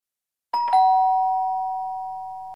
pedidosya_ding_dong.mp3